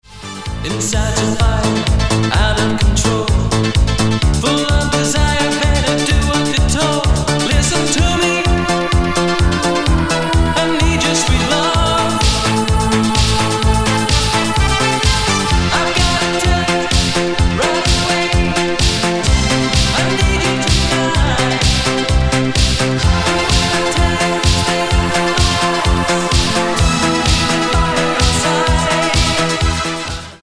The Disco Star of the 80s!